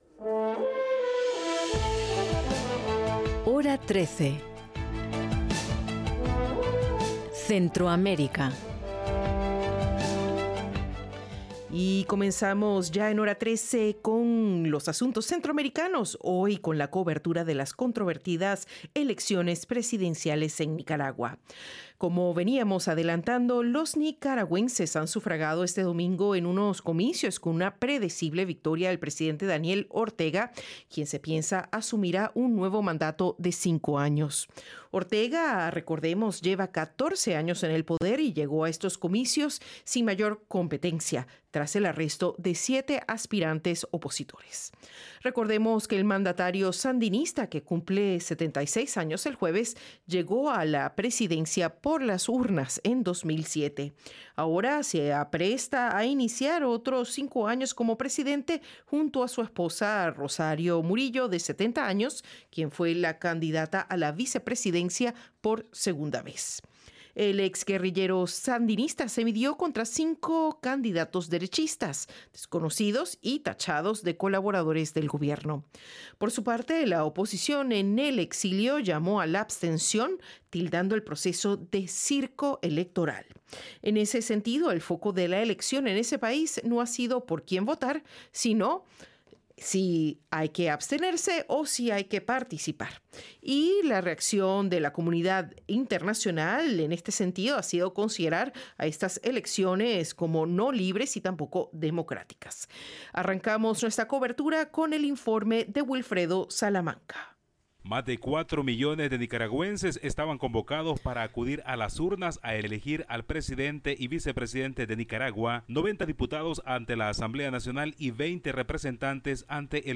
Nicaragua realizó elecciones generales en las que la oposición política llamó a no votar y el presidente Daniel Ortega se aseguraba un quinto mandato. Con potenciales candidatos opositores presos, cinco aspirantes casi desconocidos disputaron el mandato al excomandante guerrillero quien destacó el valor del voto sobre las armas. Con el informe